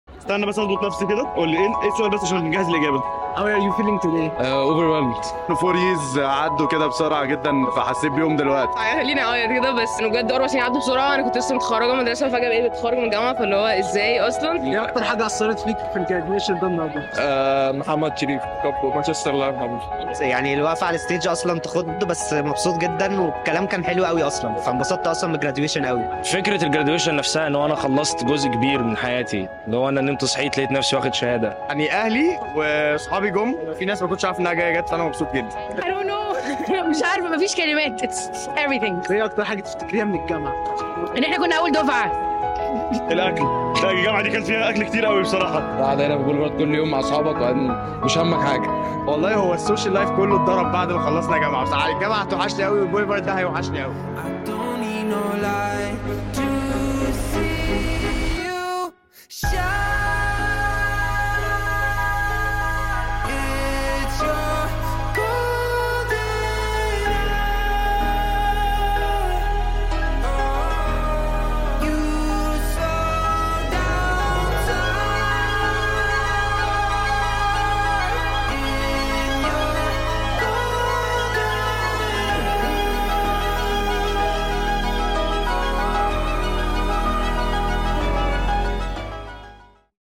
Our inaugural graduation ceremony for Coventry University branch at TKH took place on Friday, December 8th, 2023, and the excitement is still palpable!